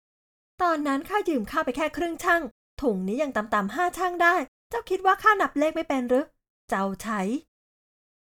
Yng Adult (18-29)